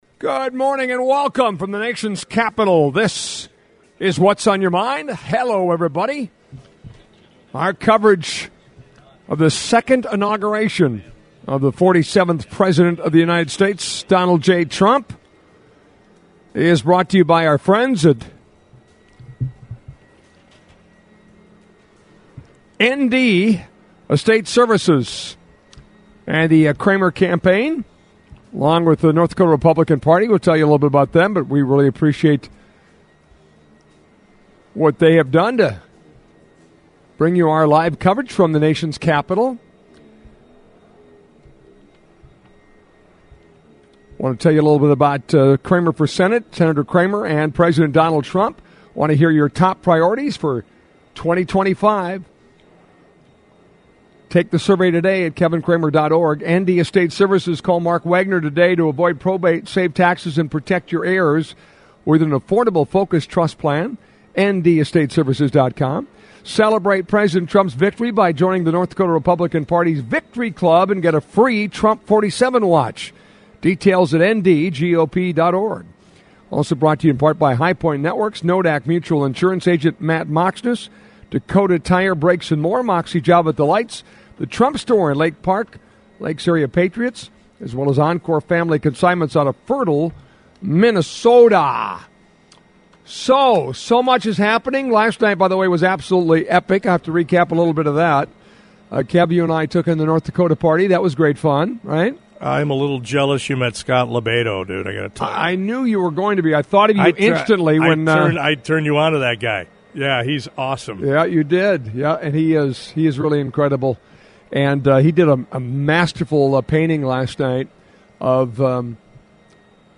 Happy Inauguration Day! Live from DC (01-20-25)